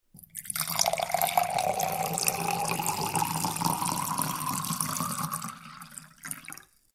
На этой странице собраны разнообразные звуки наливания воды и других жидкостей: от наполнения стакана до переливания напитков в высокий бокал.
Звук наливания в кружку